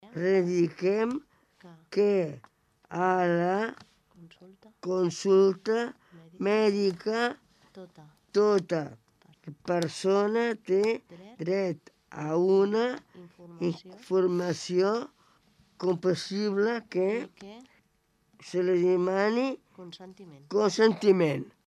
Desenes de persones van omplir la Plaça de l’Ajuntament, sobretot per escoltar el manifest d’aquest any.